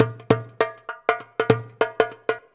100DJEMB28.wav